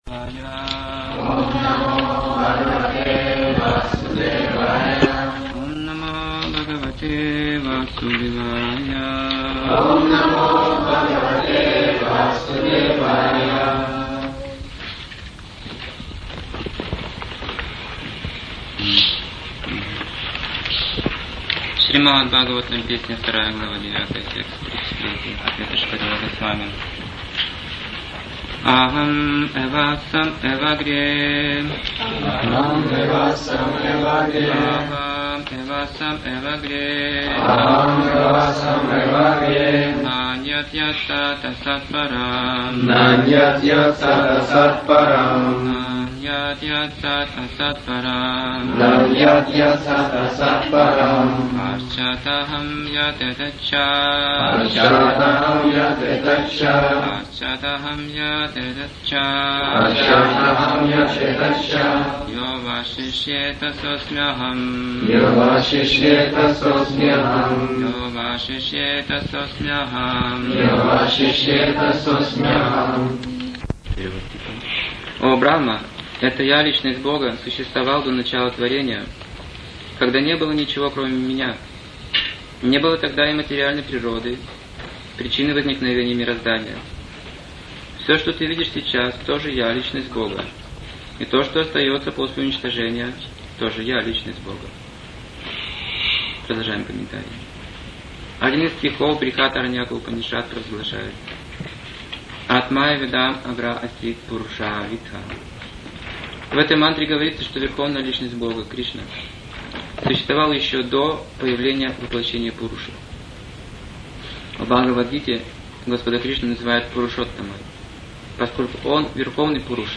Темы, затронутые в лекции: Ниргуна Отдавать в знании Бесценность Господа Ограничение в знании Путь иллюзии и реальности Милость Прабхупады Причина всех причин Истинная чистота Определение культуры Платформа самоуважения Квалификация слушания Психология семейной жизни